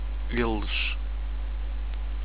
theymale.au